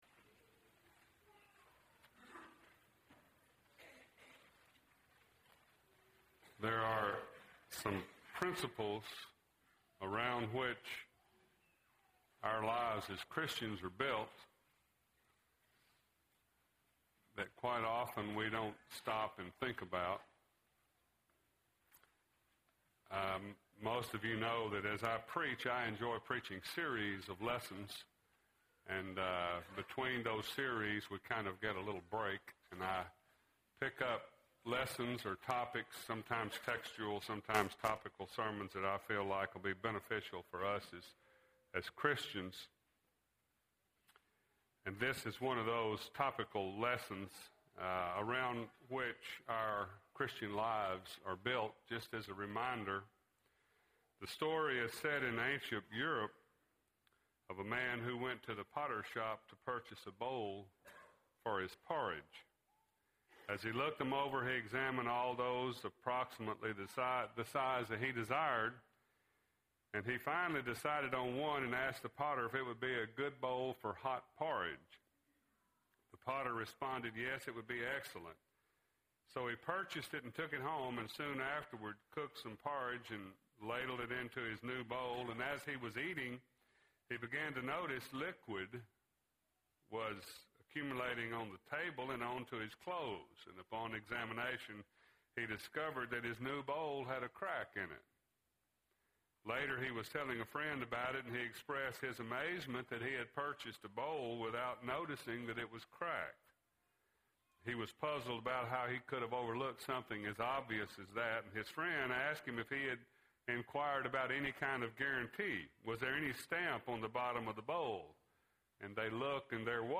Sincere – Bible Lesson Recording